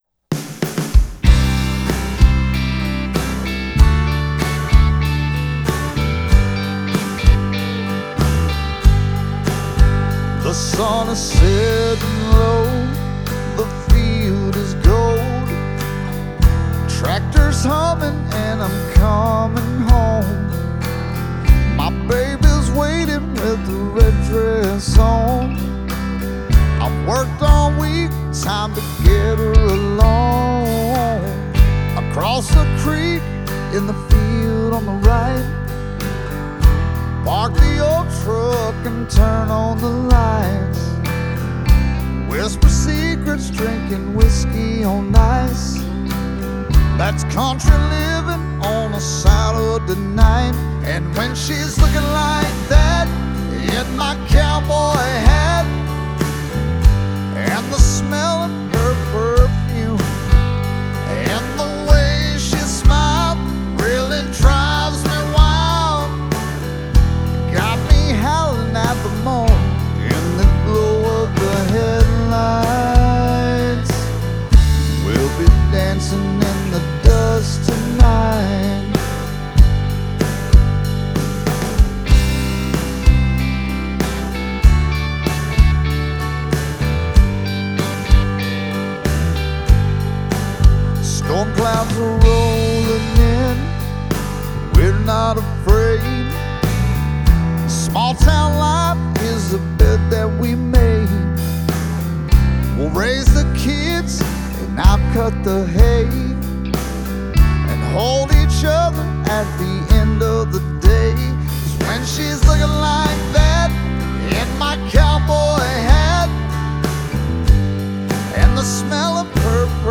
classic rock, country, and original music